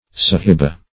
sahibah - definition of sahibah - synonyms, pronunciation, spelling from Free Dictionary Search Result for " sahibah" : The Collaborative International Dictionary of English v.0.48: Sahibah \Sa"hi*bah\, n. [See Sahib .] A lady; mistress.